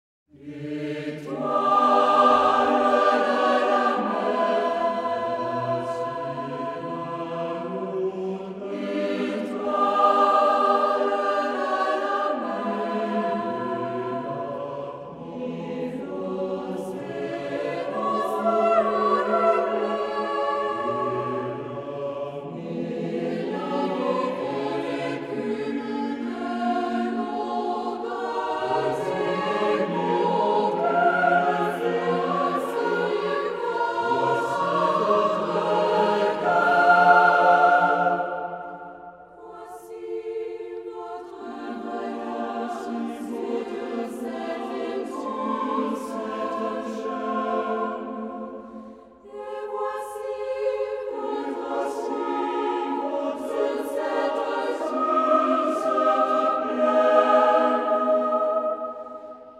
For SATB choir - 4 mixed voices